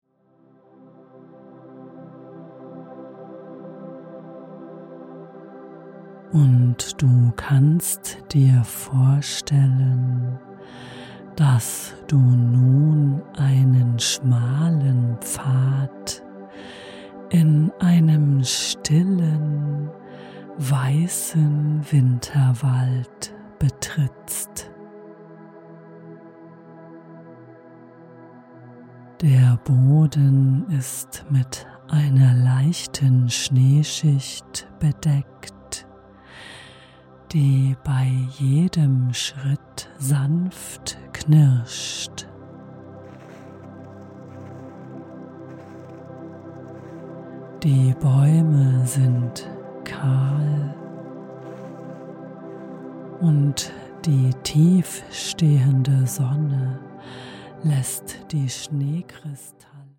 Fantasiereise Winterwald als mp3-Download
Sie ist friedvoll, beruhigend und voller innerer Weite.